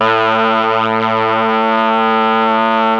RED.BRASS  3.wav